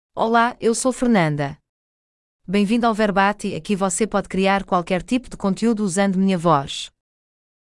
Fernanda — Female Portuguese (Portugal) AI Voice | TTS, Voice Cloning & Video | Verbatik AI
Fernanda is a female AI voice for Portuguese (Portugal).
Voice sample
Female
Fernanda delivers clear pronunciation with authentic Portugal Portuguese intonation, making your content sound professionally produced.